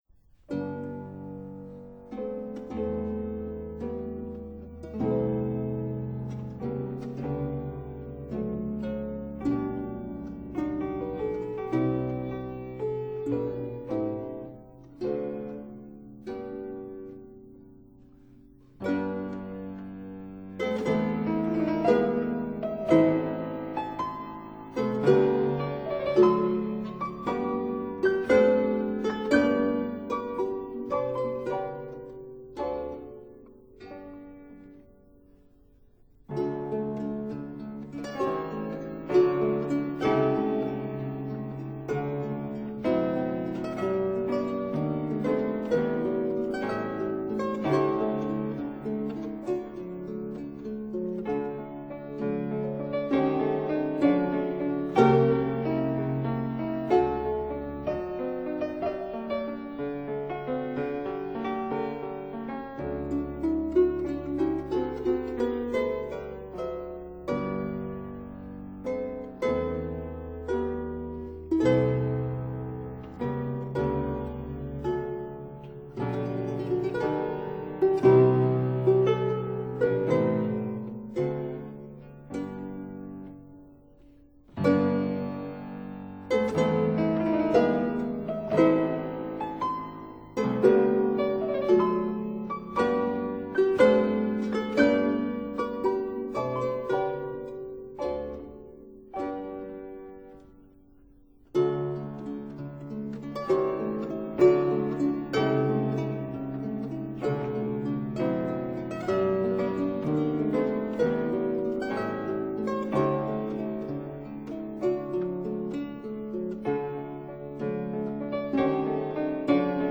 Single Action Harp
(Period Instruments)